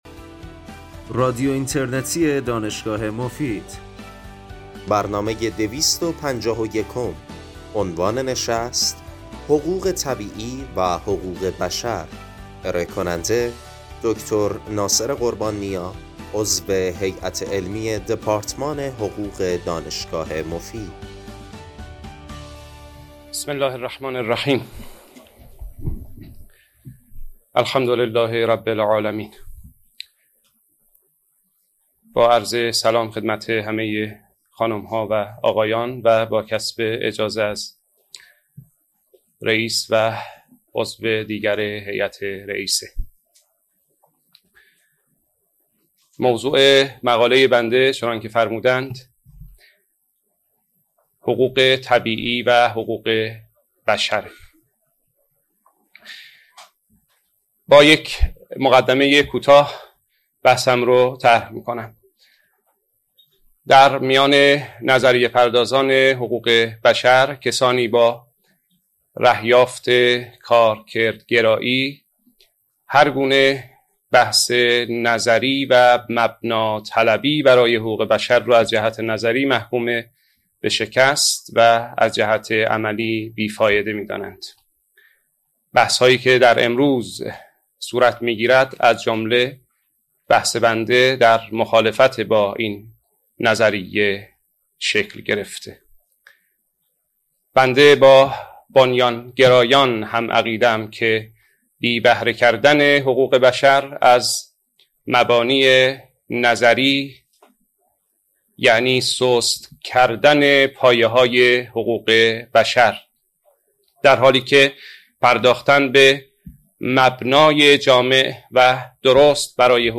این سخنرانی در سال ۱۳۸۶ و در چهارمین همایش بین المللی حقوق بشر دانشگاه مفید ایراد شده است.
بخش پایانی برنامه به پرسش و پاسخ اختصاص دارد.